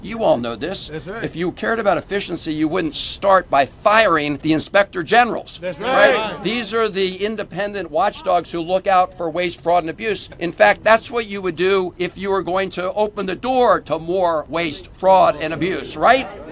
Democratic U.S. Senator Chris Van Hollen was among federal employees, union leaders and lawmakers who held a rally on Tuesday outside the Office of Personnel Management.  The rally accused President Trump and Elon Musk of illegal attacks on civil servants and the services they provide.